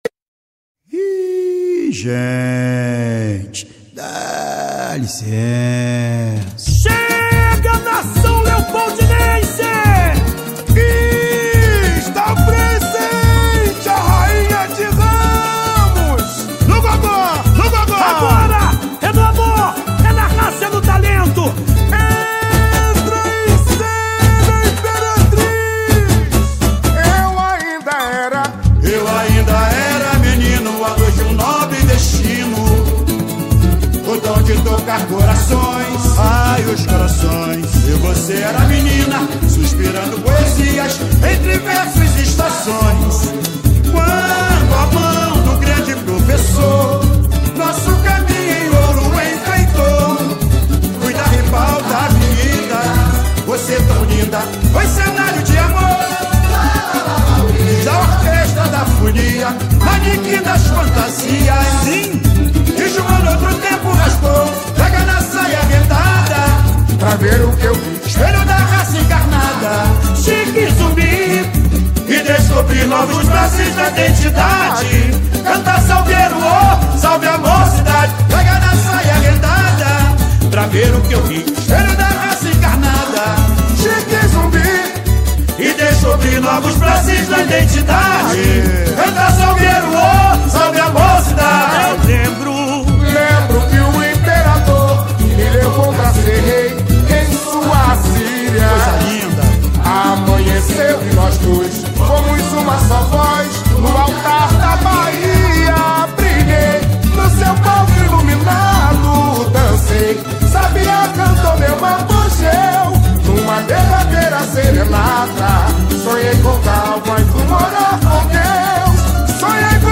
definiu o seu samba-enredo do carnaval 2022.